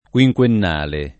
quinquennale [ k U i j k U enn # le ] agg.